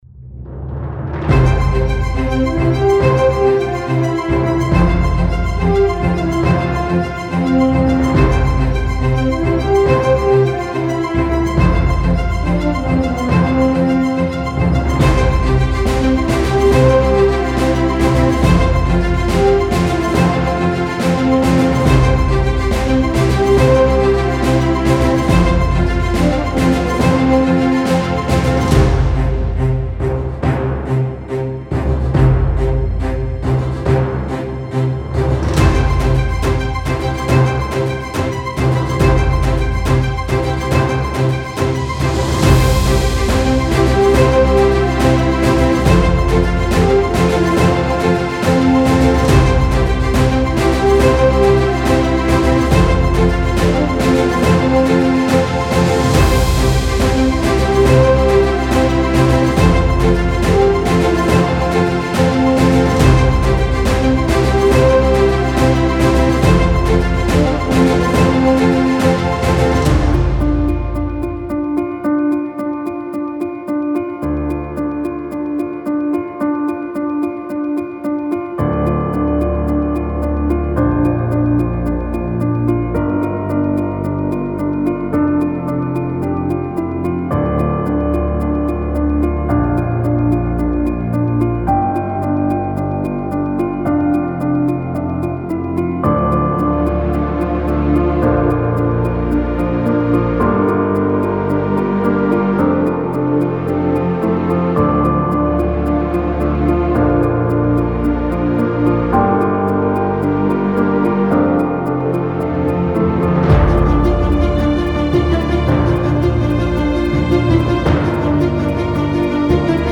Type: Midi Templates Samples
Cinematic / FX
(100 – 150 BPM).